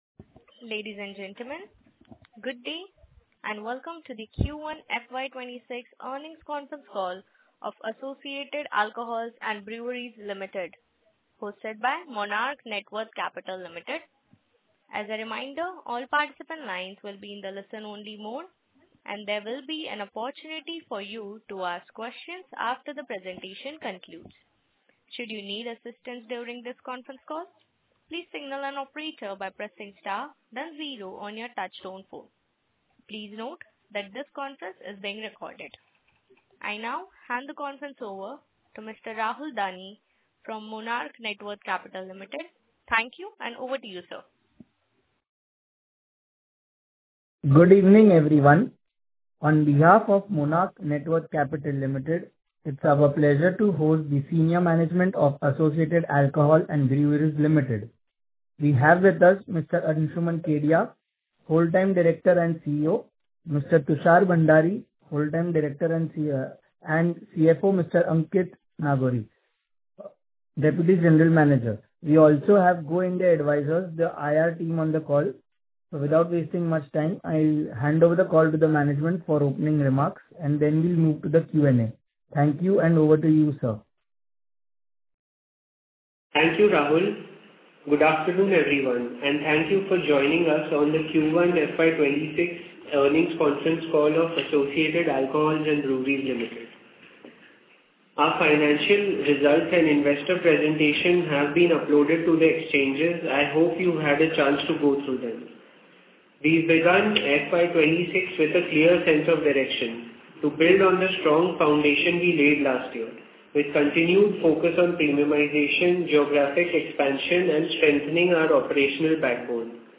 Associated-Alcohols-and-Breweries-Ltd-Q1FY26-Earnings-Call-Audio.mp3